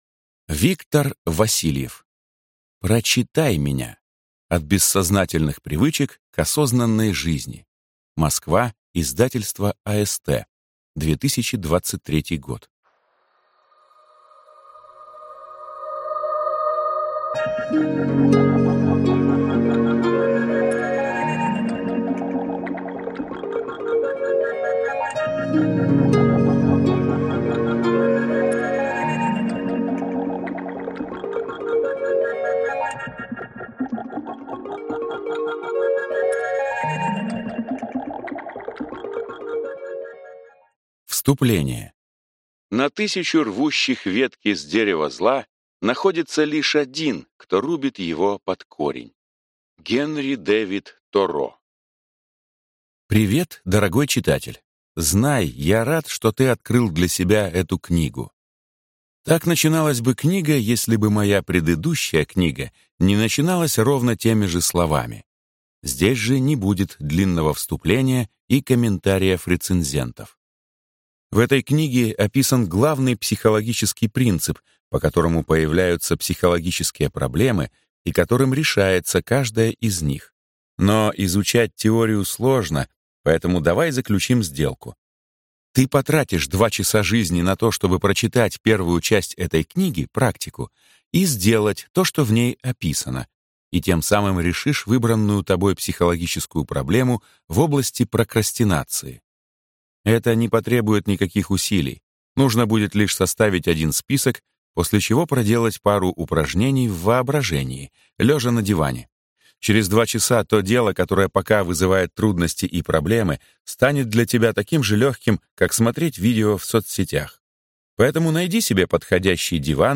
Аудиокнига Прочитай меня. От бессознательных привычек к осознанной жизни | Библиотека аудиокниг